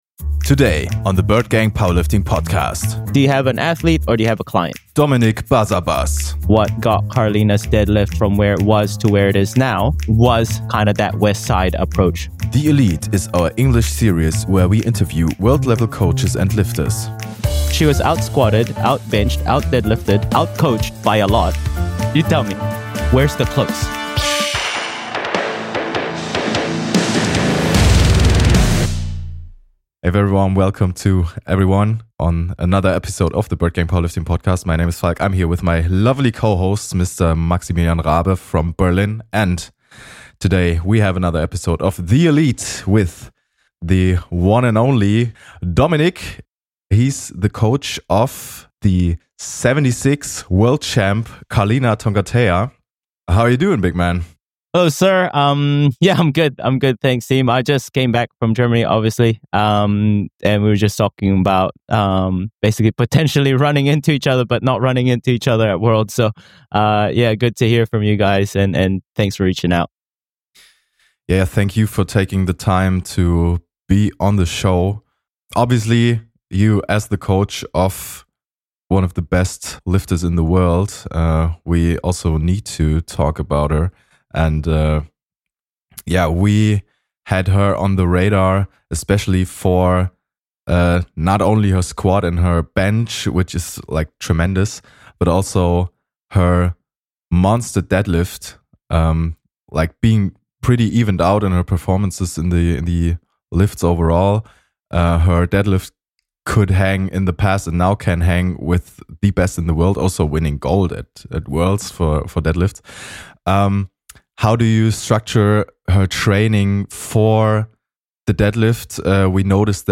THE ELITE is our english series where we interview world level coaches and lifters.